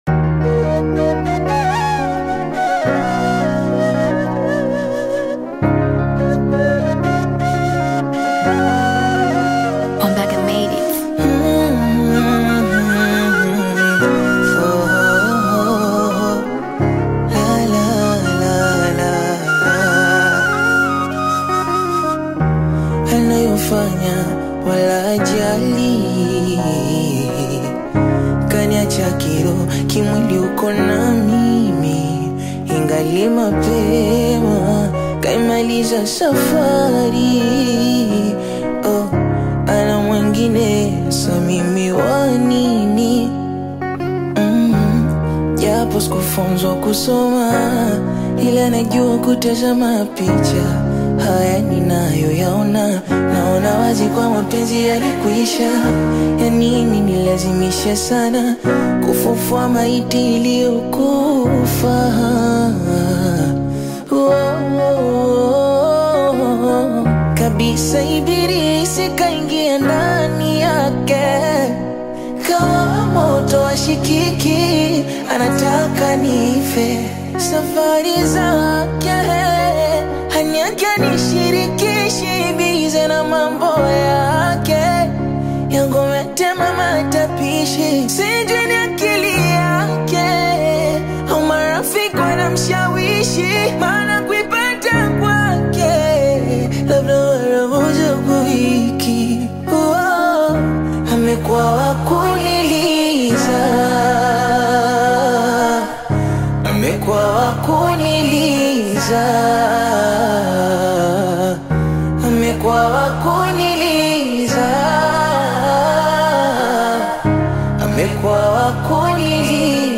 is a raw and intimate Bongo Flava/acoustic version
Genre: Bongo Flava